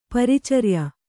♪ paricarya